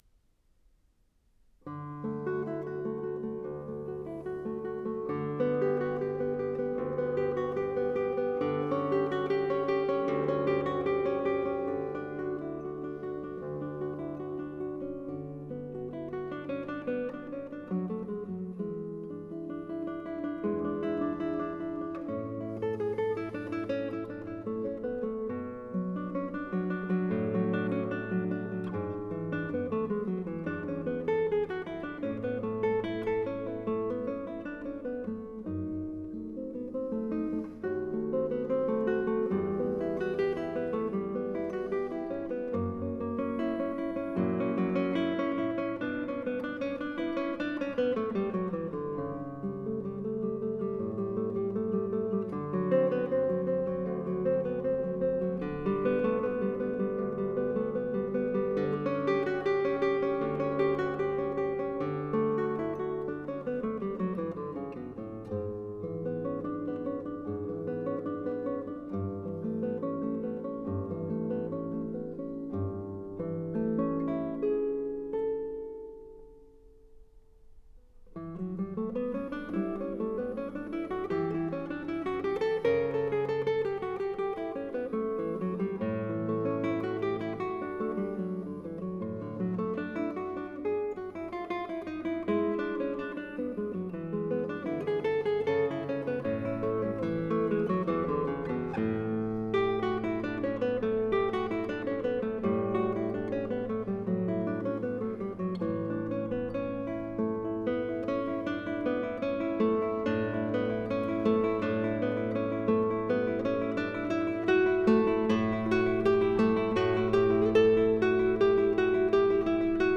The six Cello Suites, BWV 1007–1012, are suites for unaccompanied cello by Johann Sebastian Bach (1685–1750).